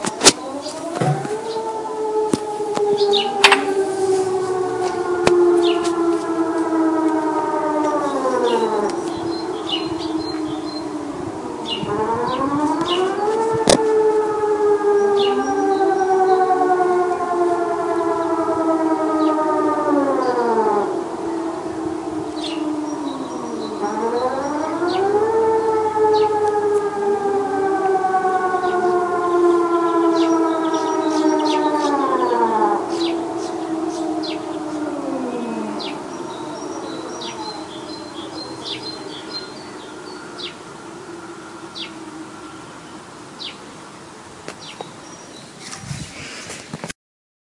警报器 " 空袭警报器长
描述：5月2日正在测试空袭警报器
Tag: 鸟类 警报器 realsound